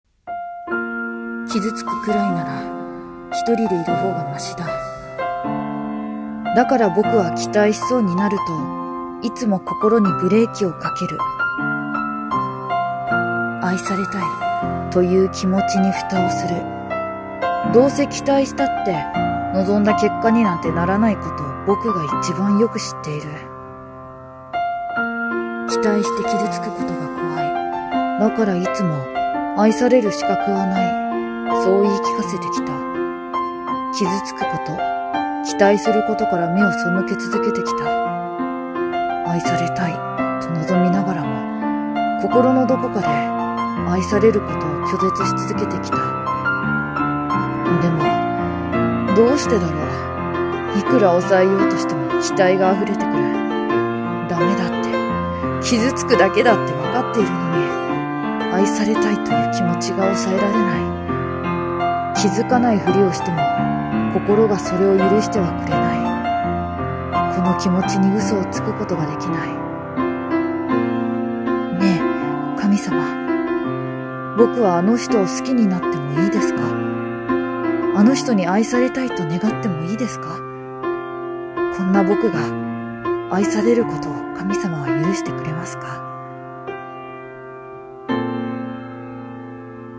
【1人声劇】期待する心